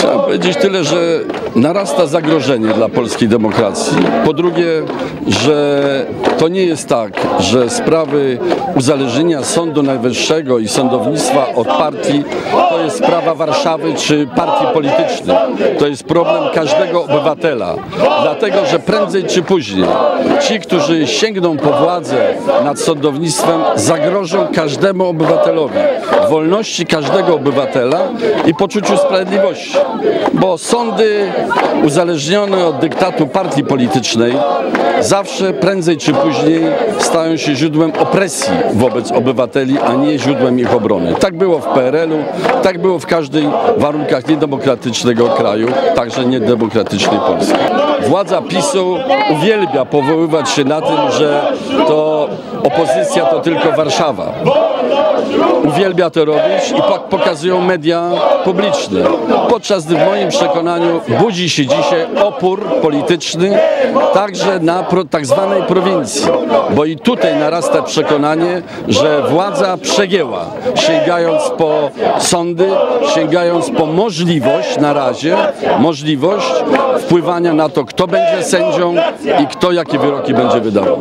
W rozmowie z Radiem 5 były prezydent powiedział, że zagrożenie dla polskiej demokracji narasta. Zdaniem Bronisława Komorowskiego głos z Augustowa może zmienić bardzo wiele, bo pokazuje, że opór polityczny budzi się również na tak zwanej prowincji.
protest-w-obronie-demokracji-3.mp3